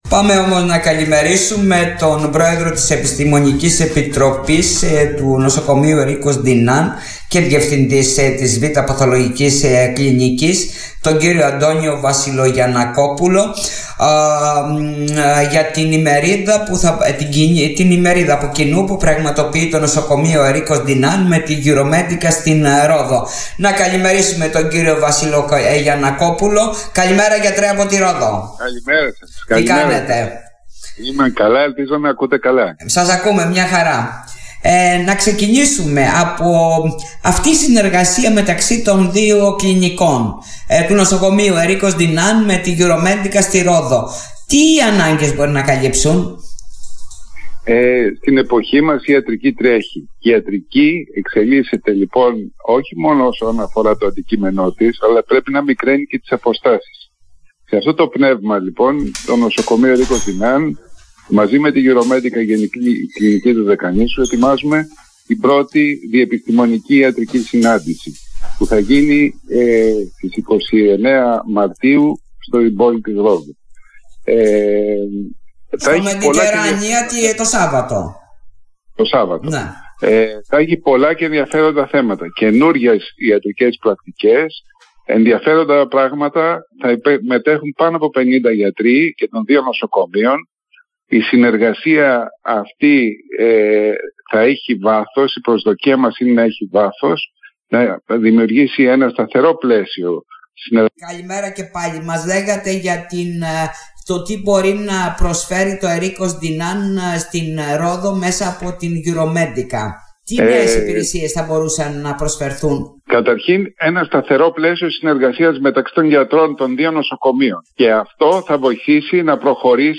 για τον TOP FM